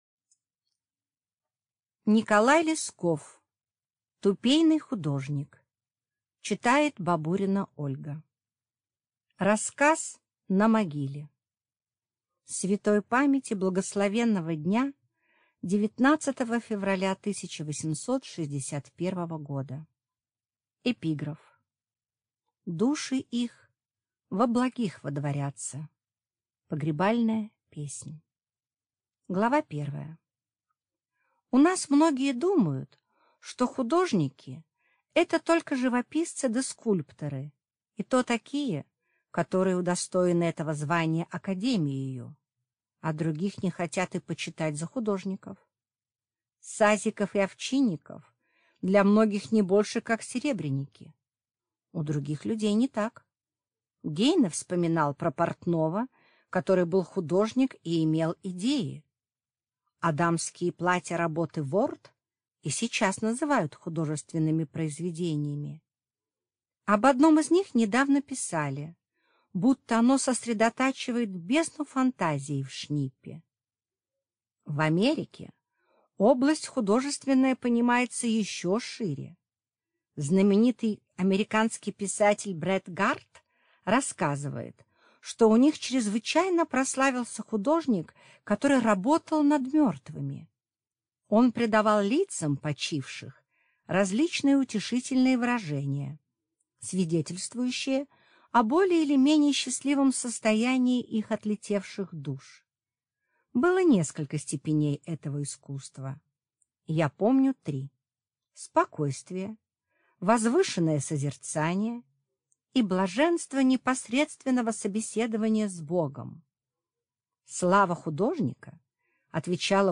Аудиокнига Тупейный художник | Библиотека аудиокниг